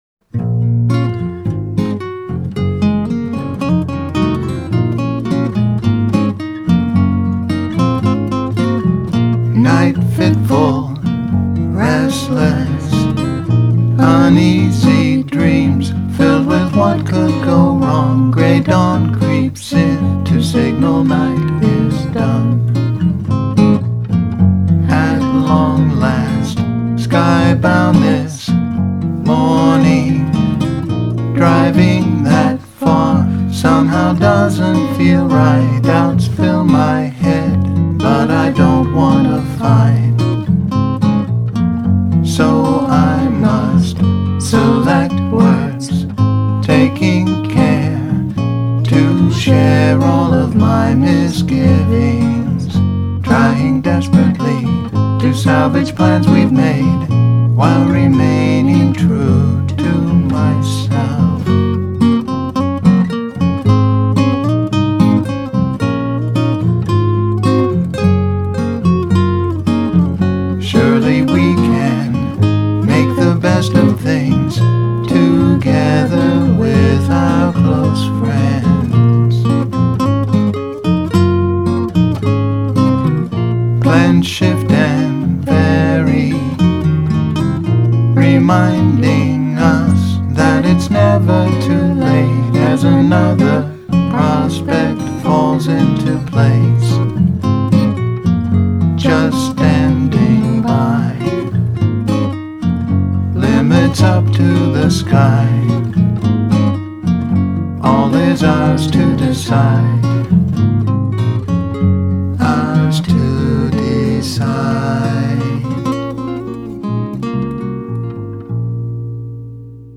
guitar, vocals and production